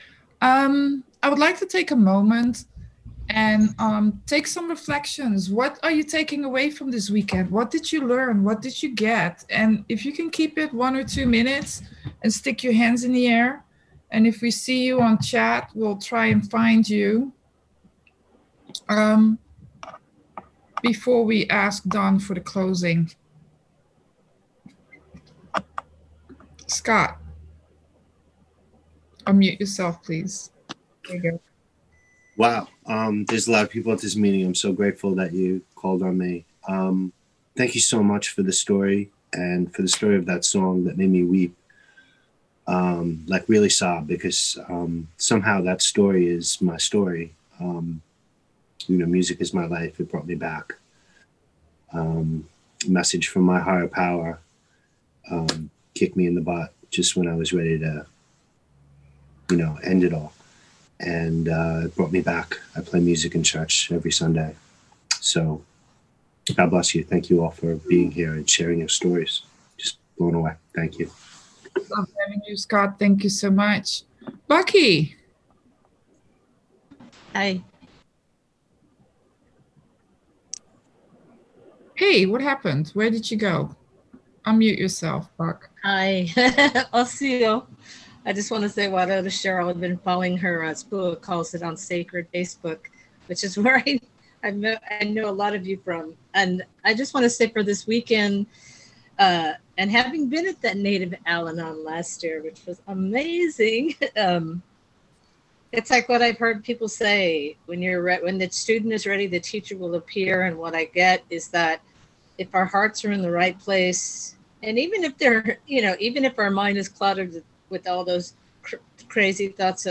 American Indian Conference - AWB Roundup Oct 17-18 - Reflections and Closing